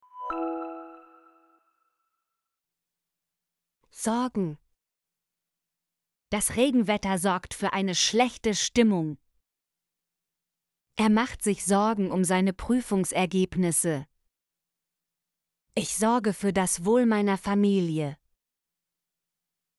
sorgen - Example Sentences & Pronunciation, German Frequency List